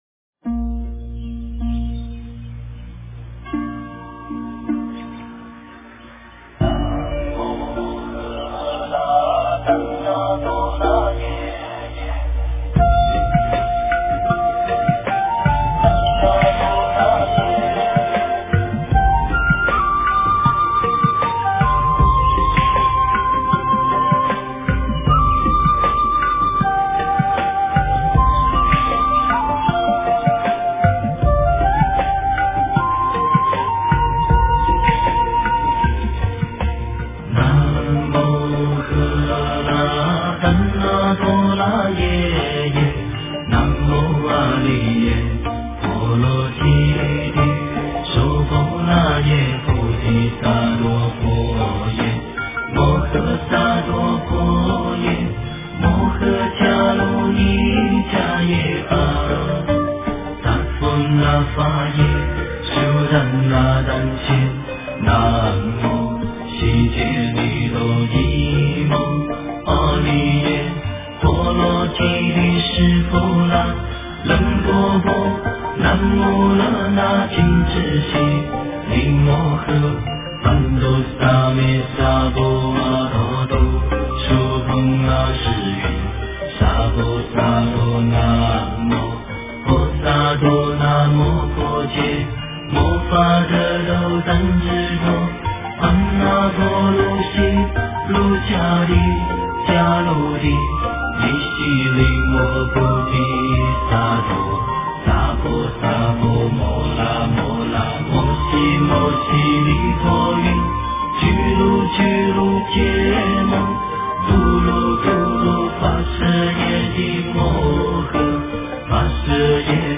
佛音 诵经 佛教音乐